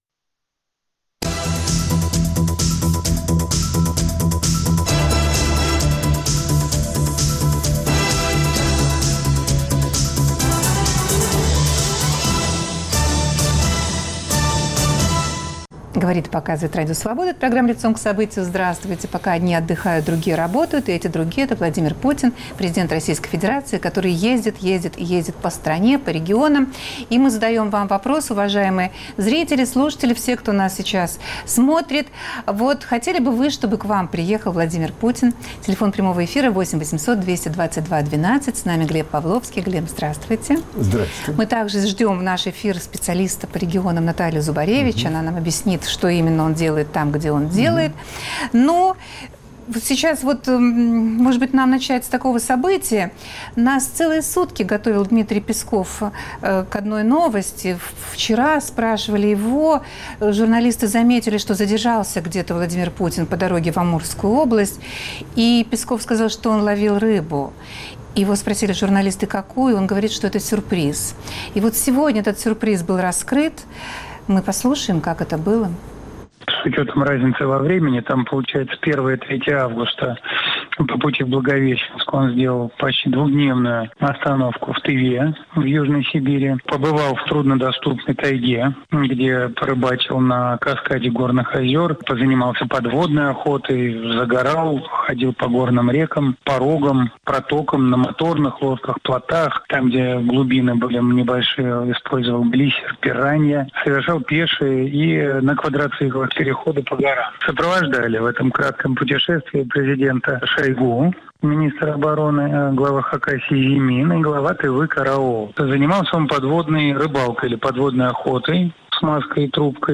Обсуждают Глеб Павловский и Наталия Зубаревич.